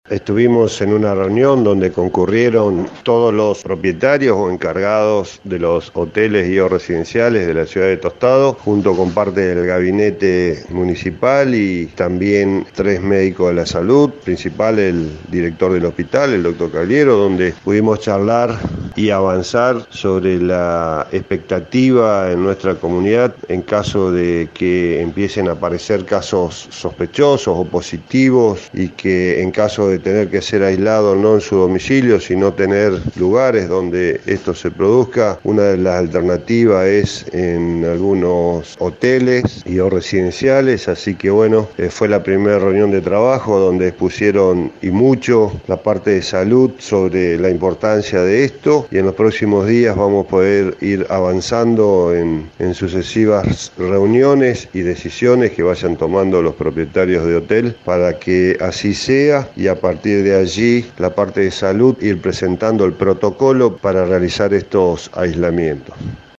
El propio intendente Enrique Mualem brindó detalles de la reunión: